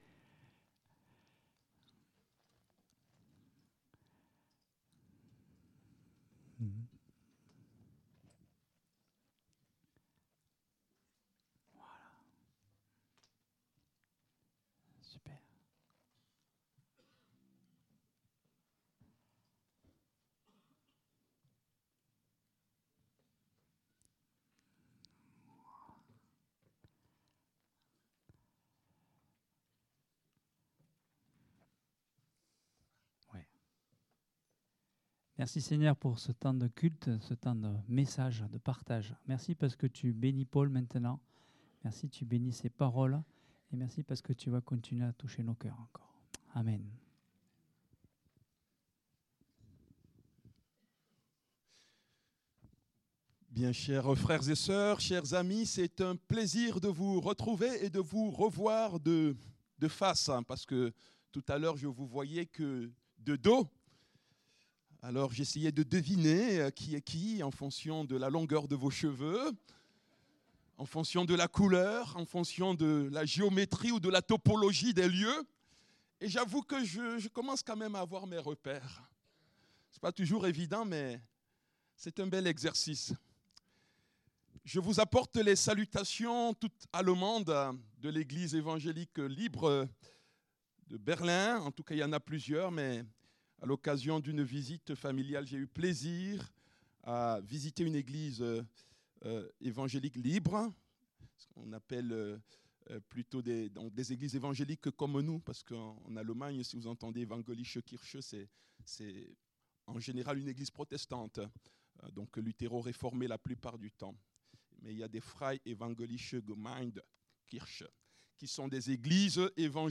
Culte du dimanche 30 mars 2025, prédication